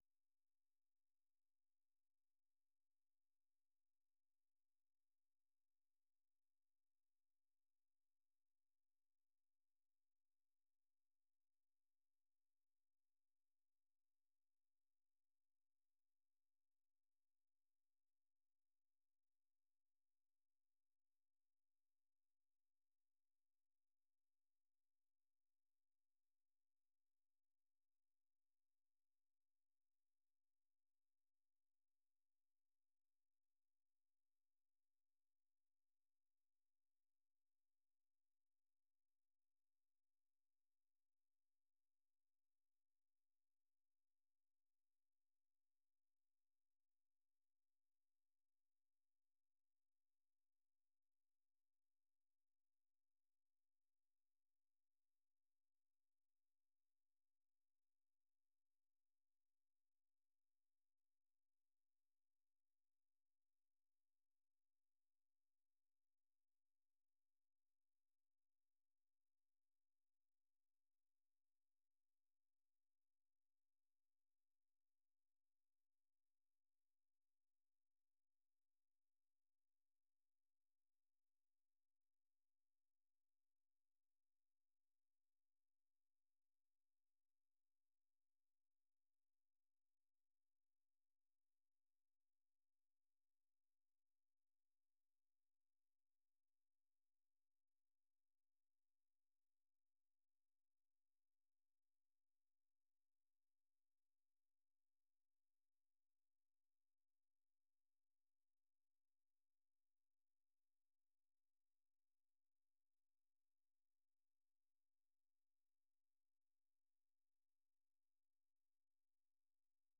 Listen Live - 생방송 듣기 - VOA 한국어